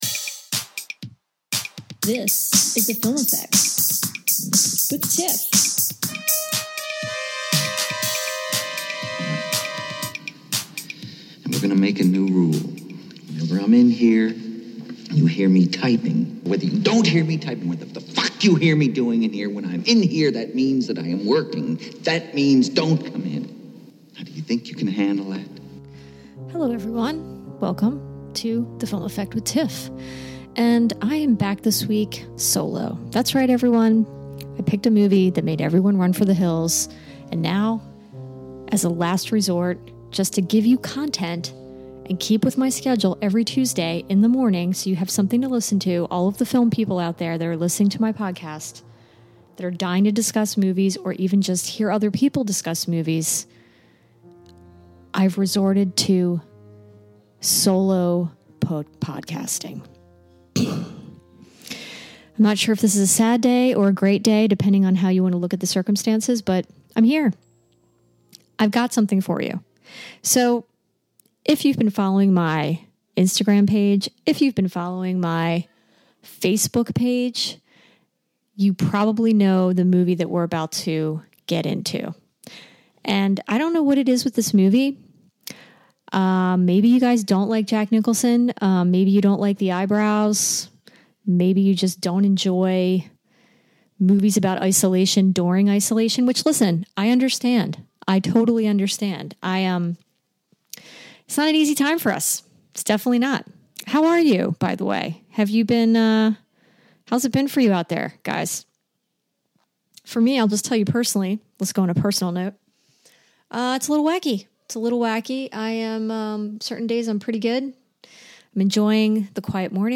This time, I'm solo since no one seems to touch this 1980 classic horror film with a ten foot pole. I enter the Overlook Hotel and join in on the crazy!